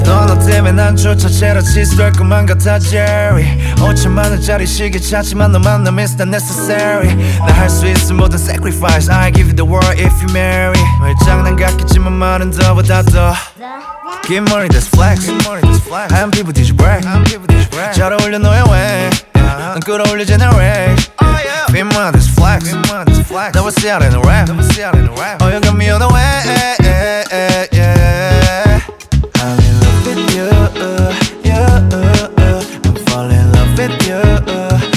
Жанр: Рэп и хип-хоп
# Korean Hip-Hop